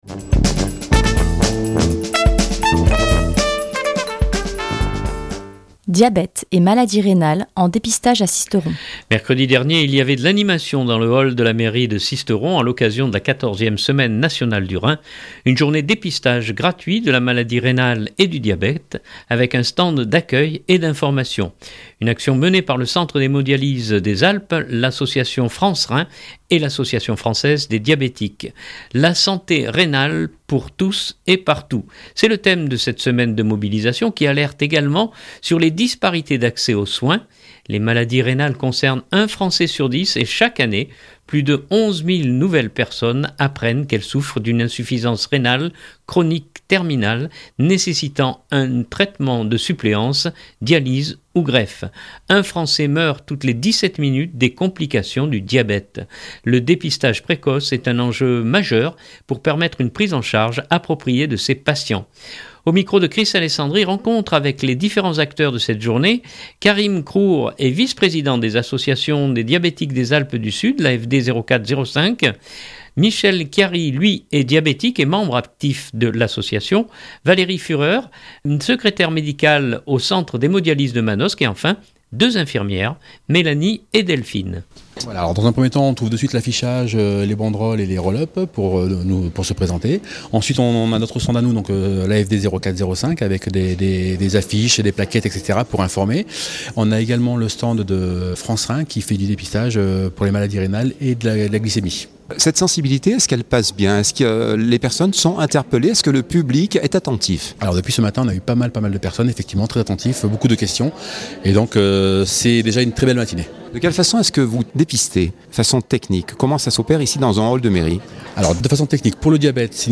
(18.1 Mo) Mercredi dernier il y avait de l’animation dans le hall de la mairie de Sisteron à l’occasion de la 14ème semaine Nationale du Rein. Une journée dépistage gratuit de la maladie rénale et du diabète avec un stand d’accueil et d’information.